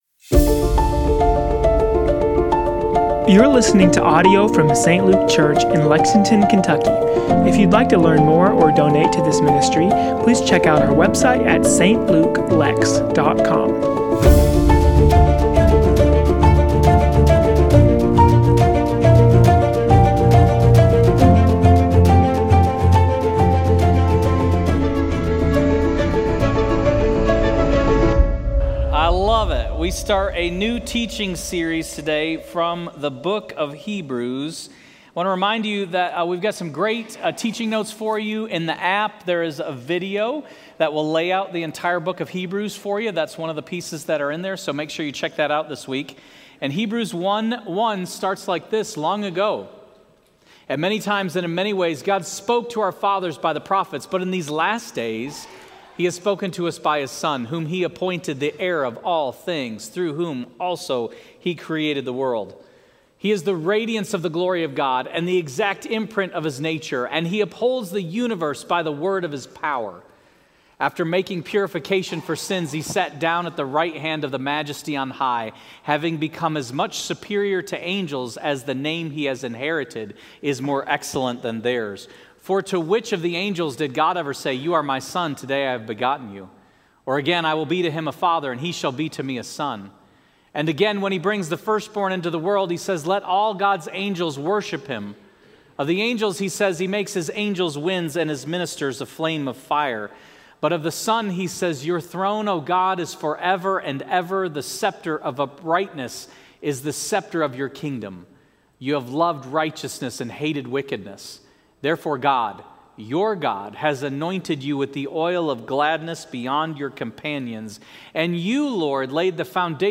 Sermon Series: Hebrews: Rediscovering Jesus